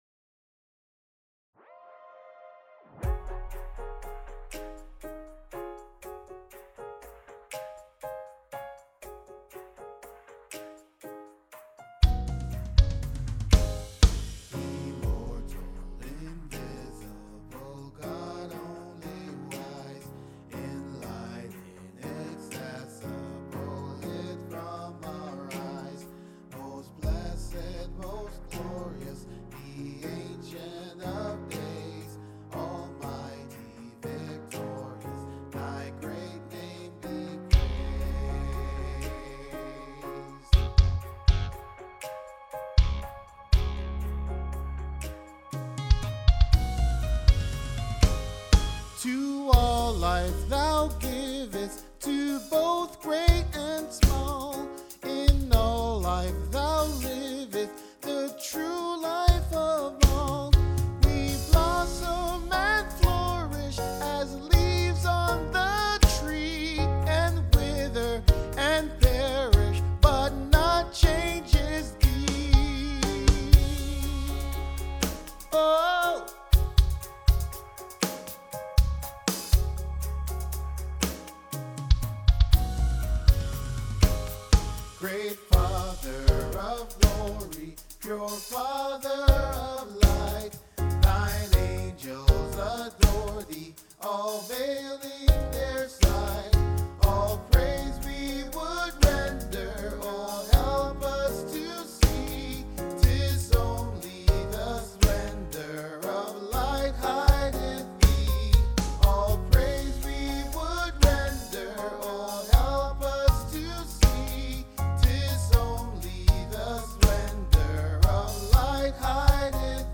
IMMORTAL INVISIBLE GOD : TENOR